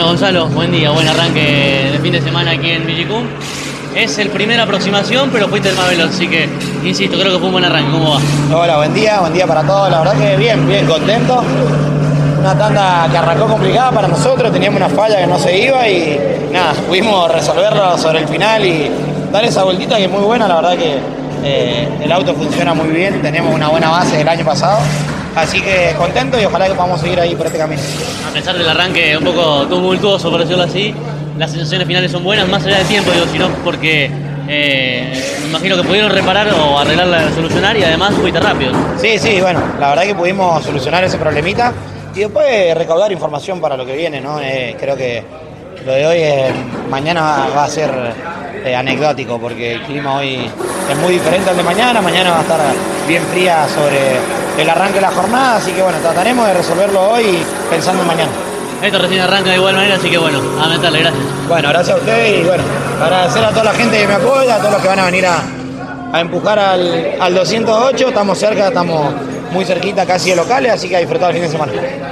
en diálogo exclusivo con CÓRDOBA COMPETICIÓN